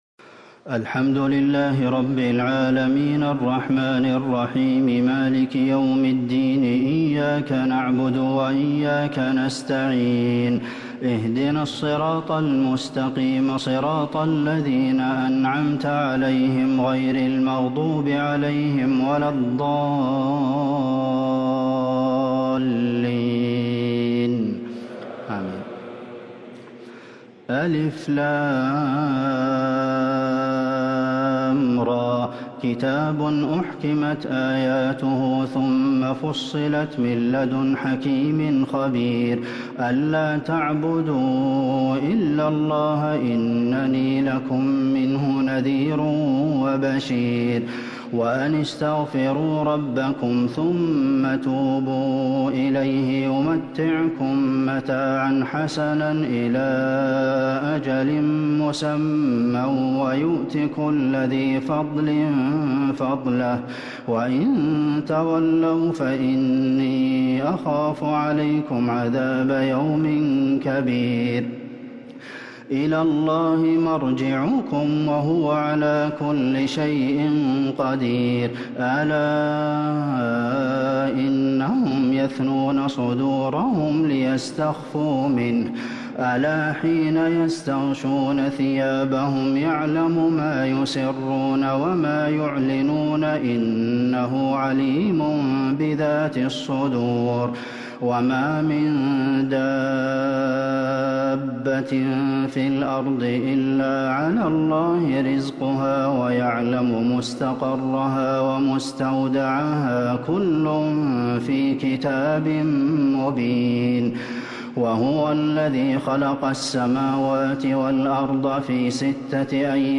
تراويح ليلة 15 رمضان 1444هـ من سورة هود (1-49) | taraweeh 15st Ramadan 1444H Surah Hud > تراويح الحرم النبوي عام 1444 🕌 > التراويح - تلاوات الحرمين